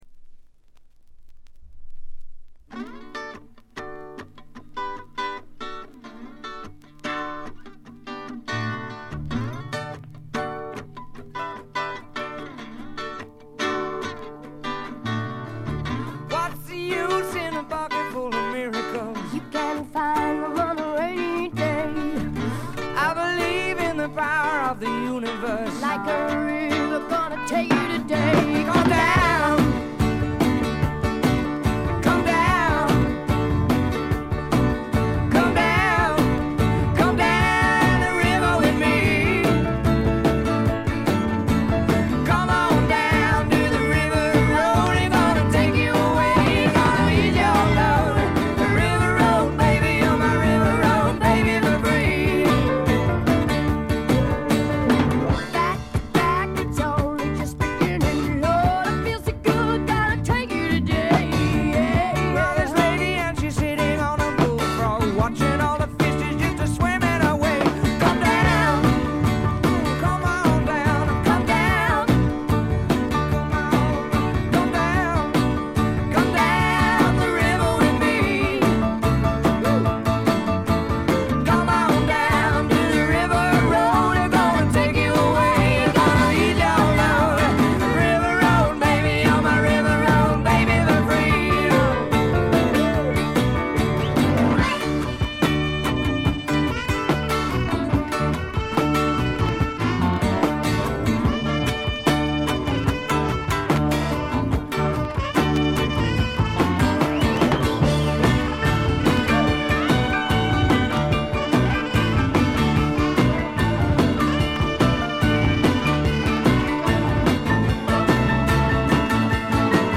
部分試聴ですが、軽微なチリプチ少々。
たまらないスライドプレイが楽しめます。
試聴曲は現品からの取り込み音源です。